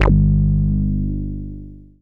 BASS02.WAV